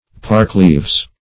parkleaves \park"leaves`\ (p[aum]rk"l[=e]vz`), n. (Bot.)